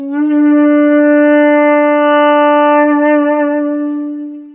We carry out the an estimation for a shakuhachi flute sound characterized by 3 segment: Rapid change of pitch (0 - 0.5 secs), fix pitch (0.5 - 3.0 secs), tremolo (3.0 - 4.0 secs), and fixed pitch to end the sound. This flute is also characterized by a noisy (windy) sound.
In the residuals sound for the large window fit we hear signal during the tremolo and for the small window size fit the fitted signal sounds too noisy, too similar to the original.